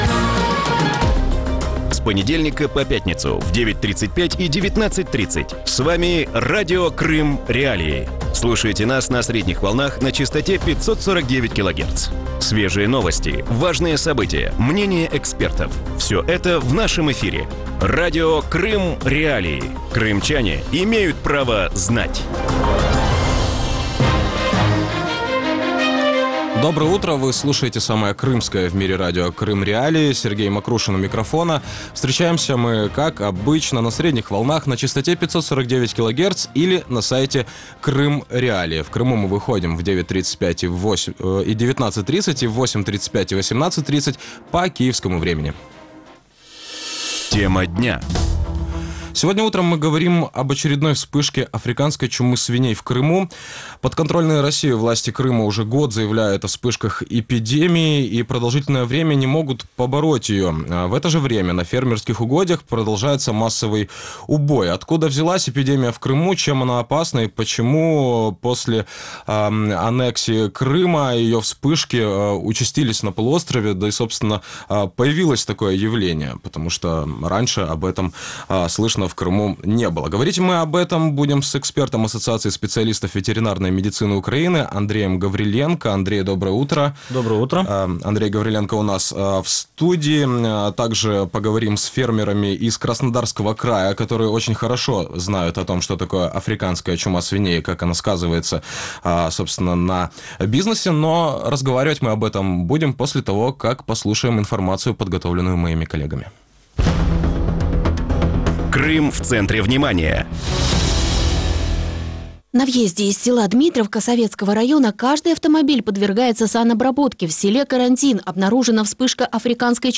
У ранковому ефірі Радіо Крим.Реалії говорять про черговий спалах африканської чуми свиней в Криму.